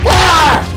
Gamer Scream Sound Button - Free Download & Play